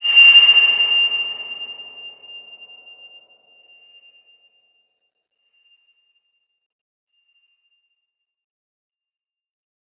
X_BasicBells-F5-pp.wav